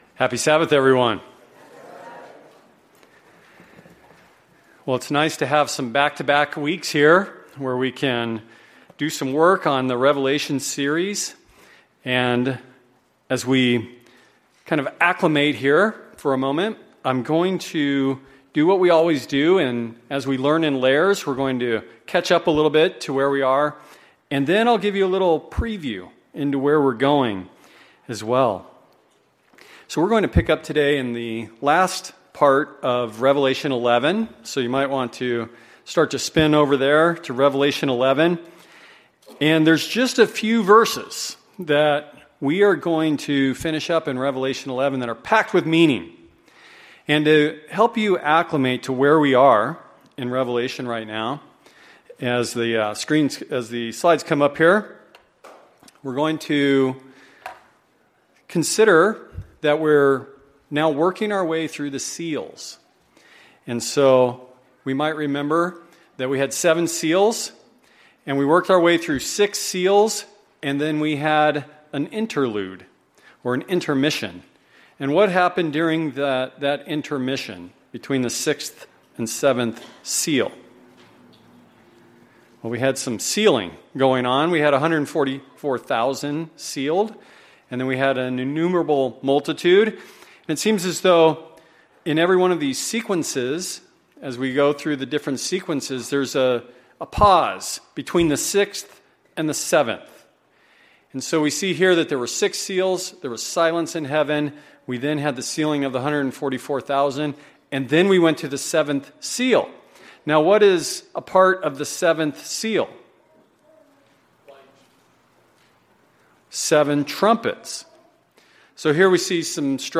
Given in Phoenix Northwest, AZ